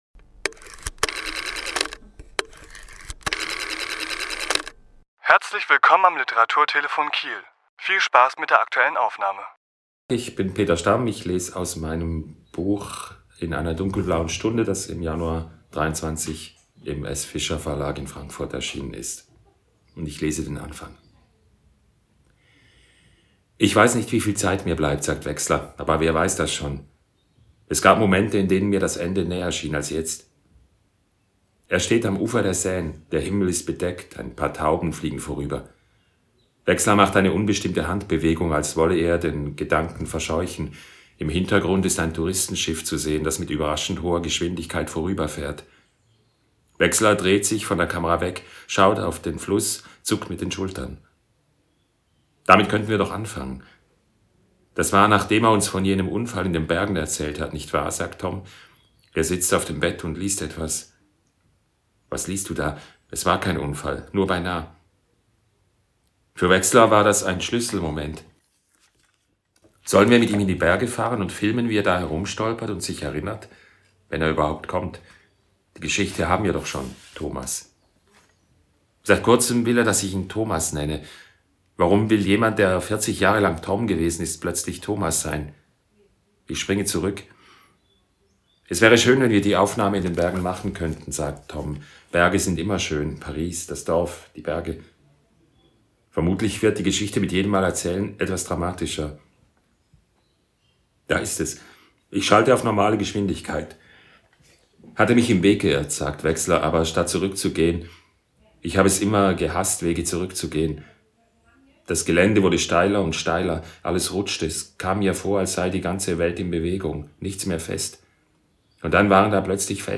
Autor*innen lesen aus ihren Werken
Die Aufnahme entstand im Rahmen einer Lesung im Literaturhaus Schleswig-Holstein am 23.3.2023.